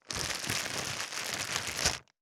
628コンビニ袋,ゴミ袋,スーパーの袋,袋,買い出しの音,ゴミ出しの音,袋を運ぶ音,
効果音